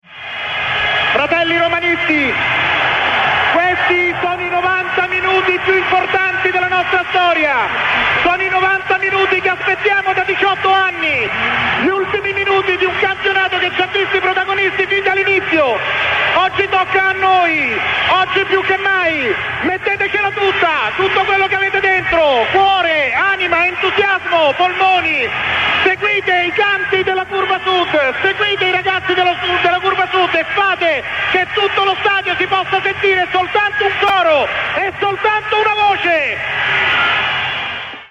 incitamento al pubblico prima della partita, l'annuncio della formazione e il triplice urlo delle 17.03: "Campioni d'Italia"!